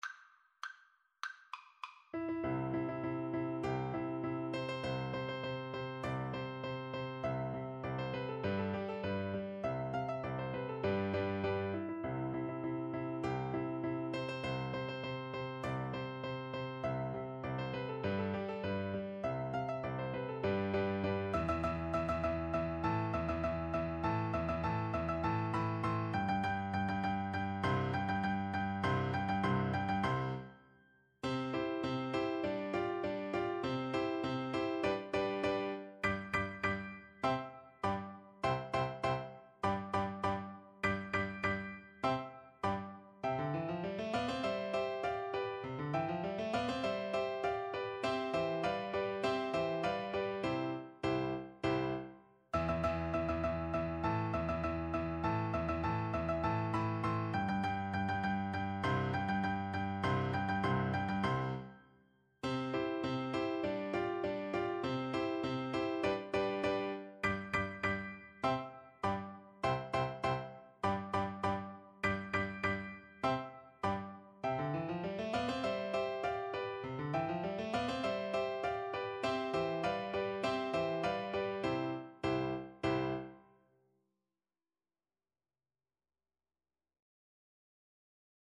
C major (Sounding Pitch) (View more C major Music for Flute )
March = c.100
Classical (View more Classical Flute Music)